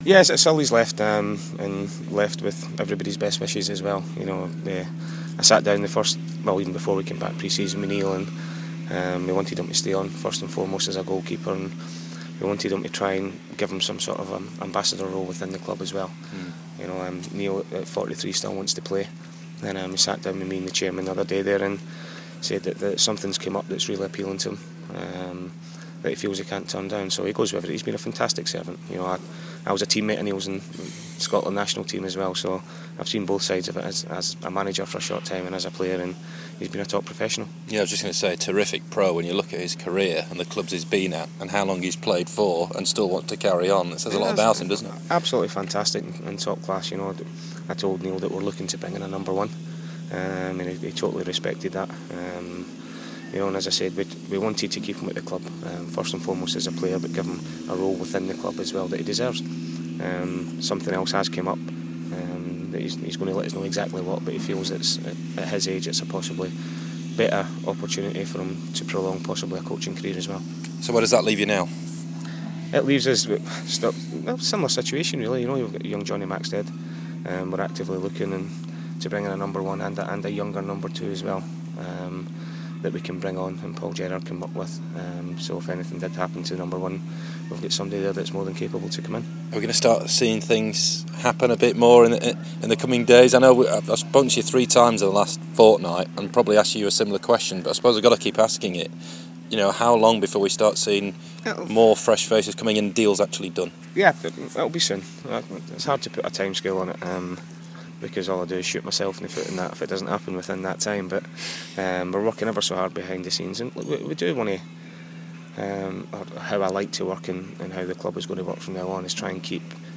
Today's interview with Doncaster Rovers boss Paul Dickov